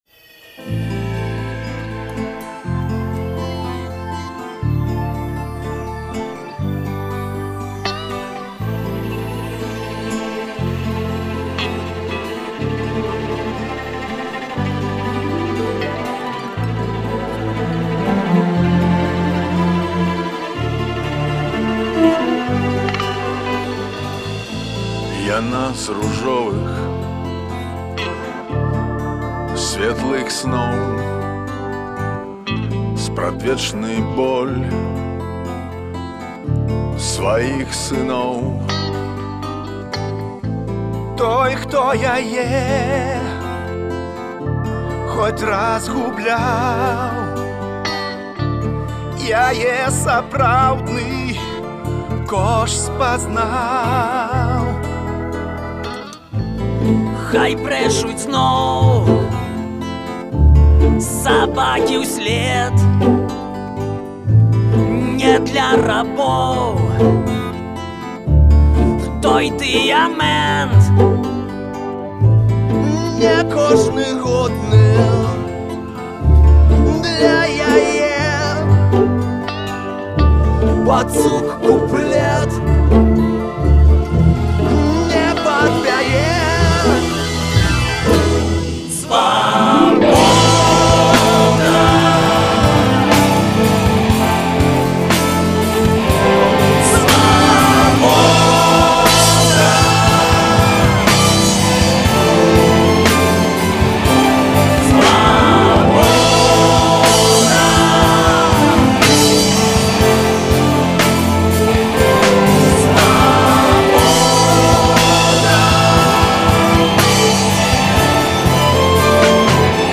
Супольны гімн беларускіх музыкаў